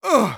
Voice file from Team Fortress 2 German version.
Medic_painsharp06_de.wav